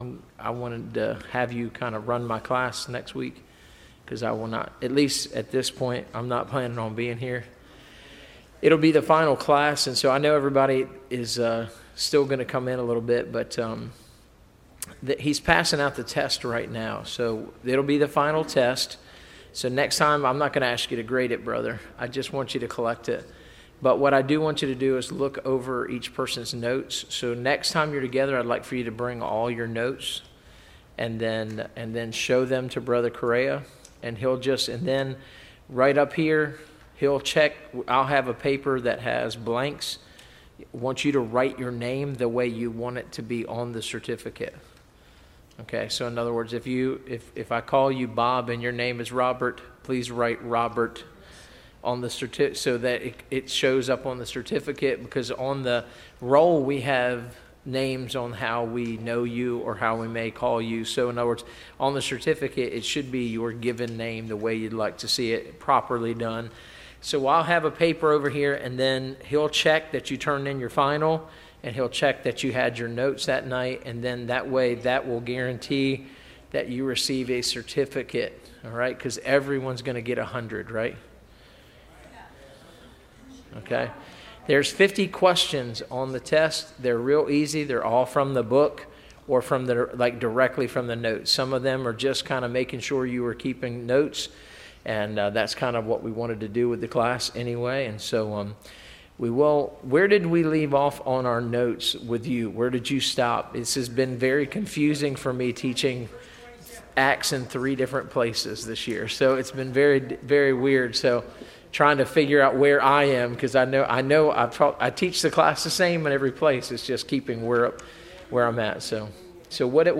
Service Type: Institute